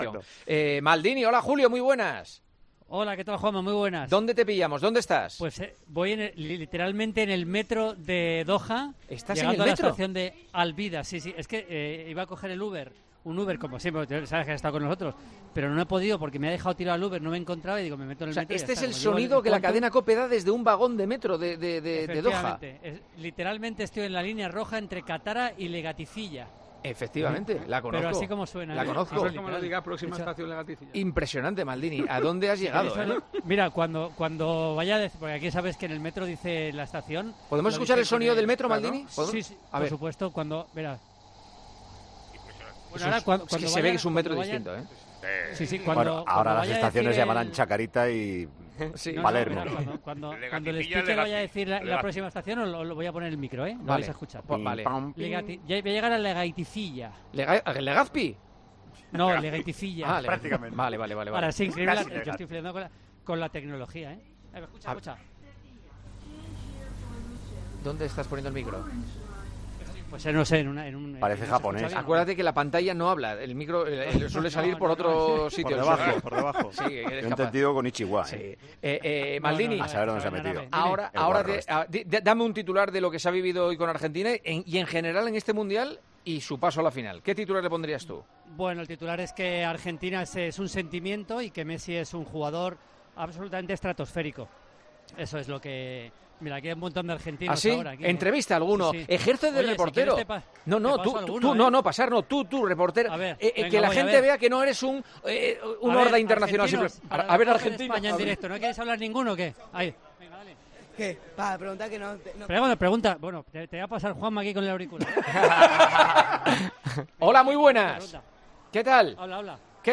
A Maldini le tocó ser reportero en el metro de Qatar y, a petición de Juanma Castaño, le hizo preguntas a la afición argentina.
En concreto, a Maldini la conexión en el programa le pilló en pleno metro de Doha, en Qatar, volviendo de la retransmisión del triunfo de Argentina ante Croacia (3-0), con la clasificación a la final del Mundial.